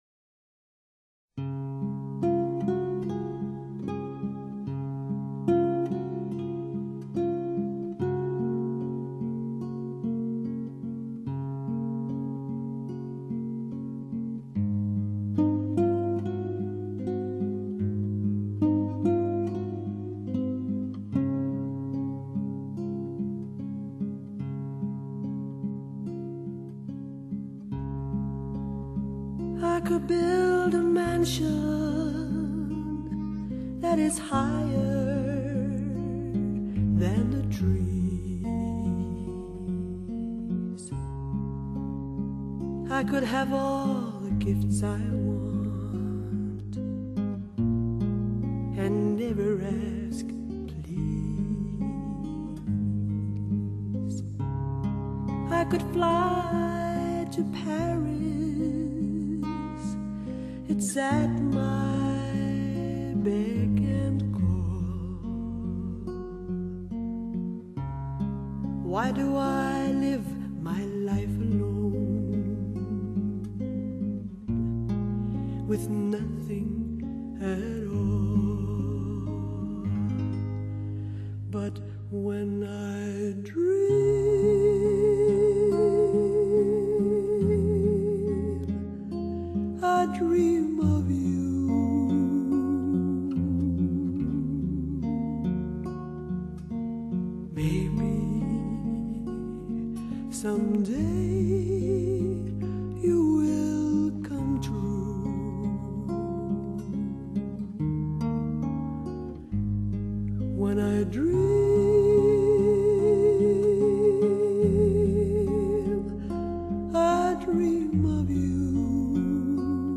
类别：发烧女声（jazz）